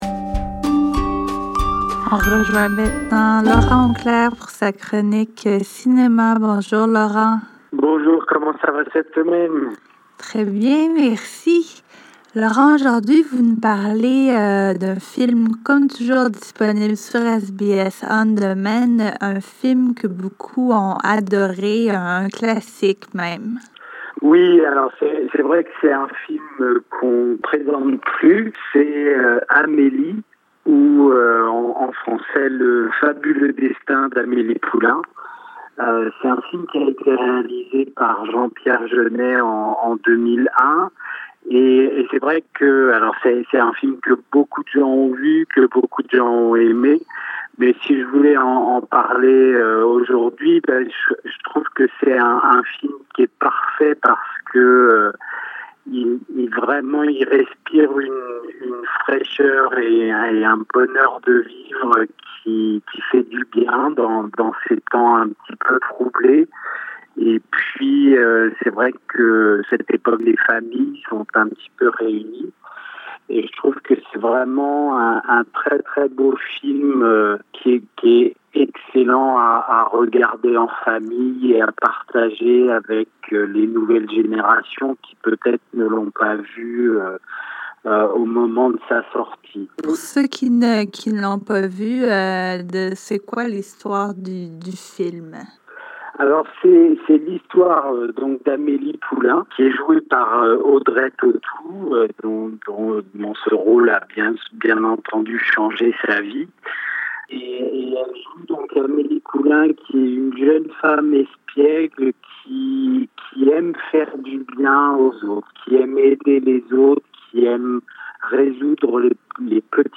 Dans notre podcast Easy French, nous vous présentons certaines de nos interviews en version ralentie à 70%, pour faciliter la compréhension. Cette semaine, notre analyse du film Amélie.